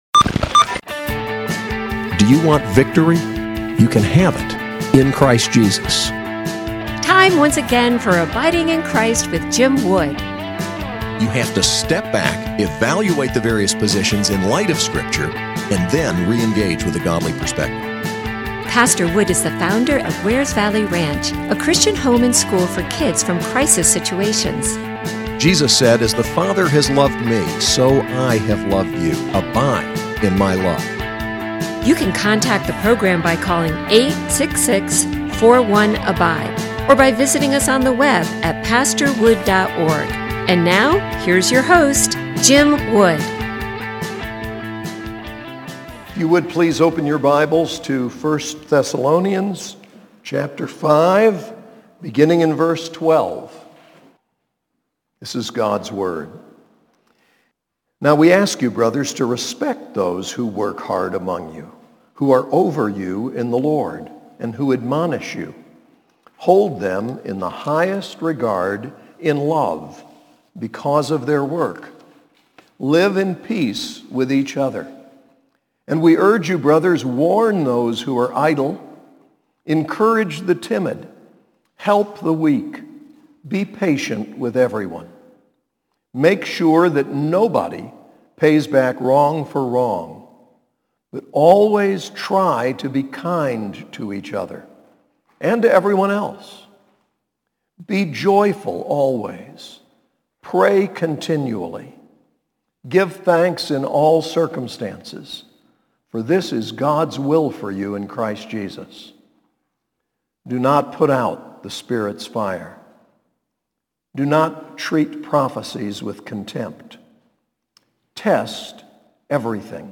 SAS Chapel: 1 Thessalonians 5:12-28